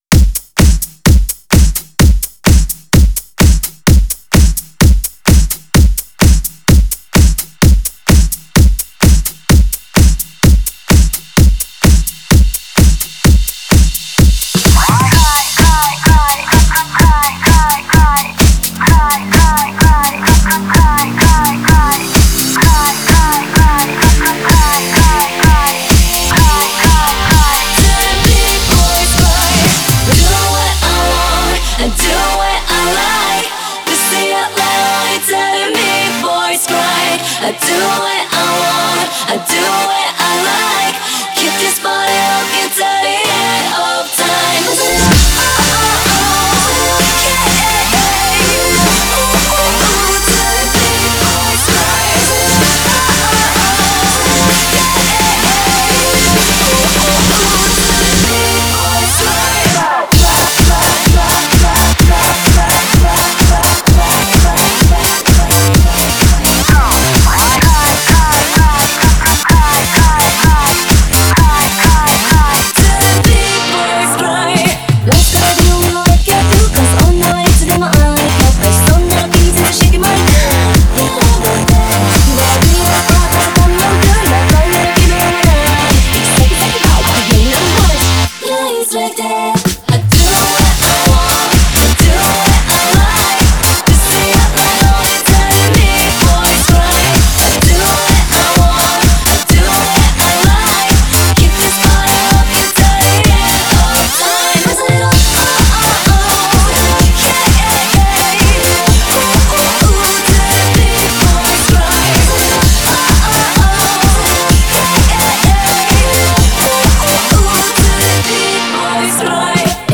Genre(s): Electro-House
Remix Video